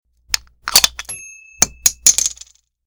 Beer Or Soda Bottle Being Opened (Cap Falls) Sound
A beer or soda bottle being opened. This sound is loud and clean with no unwanted noise.
BeerBottleOpenCapFalls.mp3